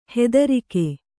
♪ hattarike